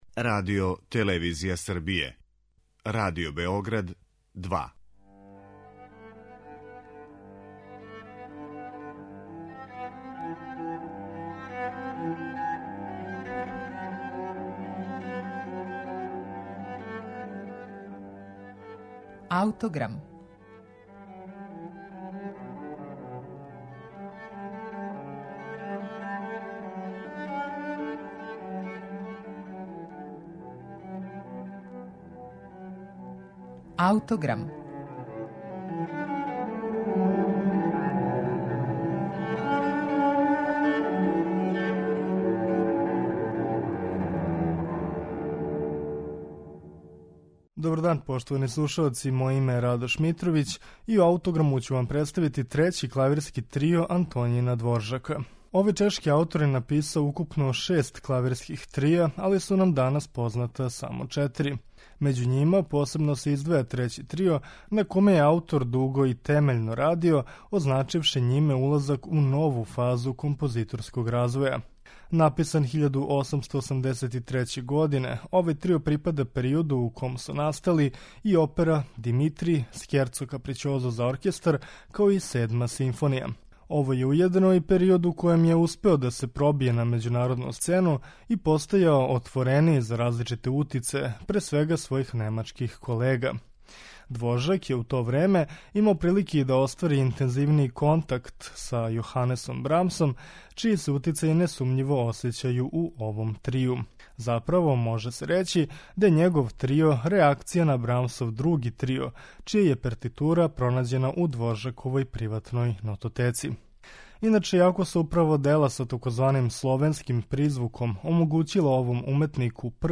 Дворжаков Трећи клавирски трио у еф-молу